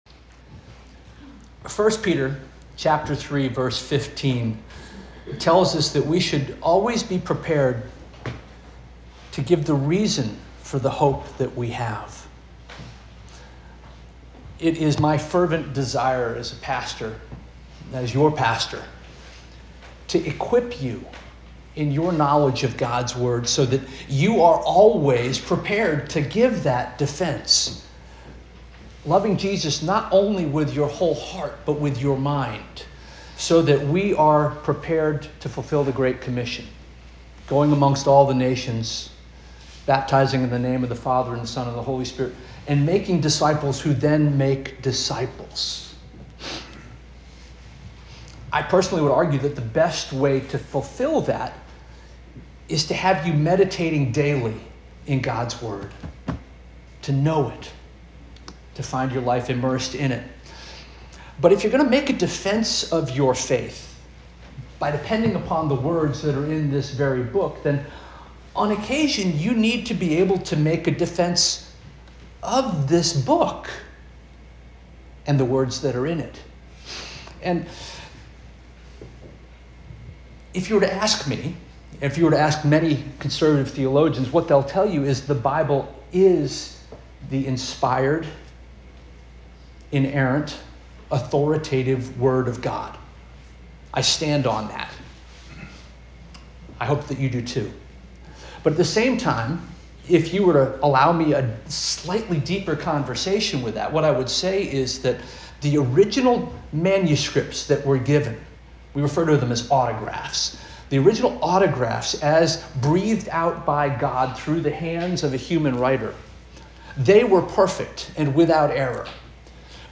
March 8 2026 Sermon - First Union African Baptist Church